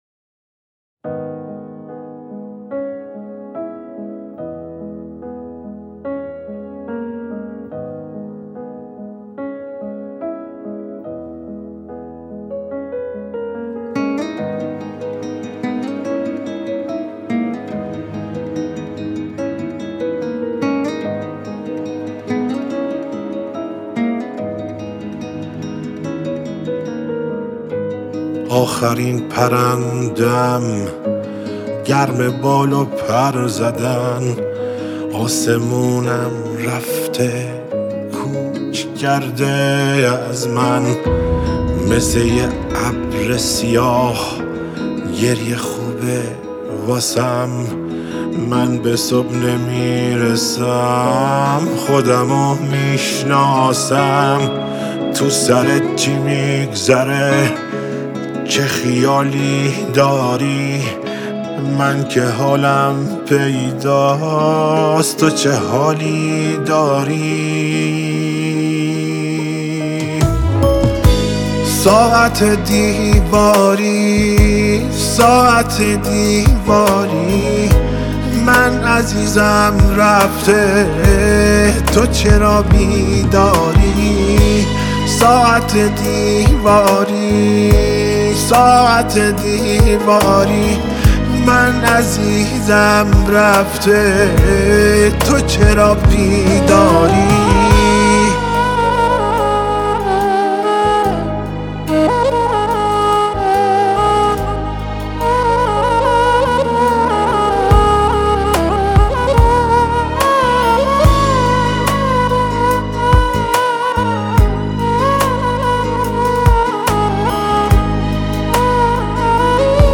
پیانو
گیتار نایلون
سولو کیبورد